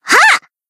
BA_V_Reisa_Battle_Shout_1.ogg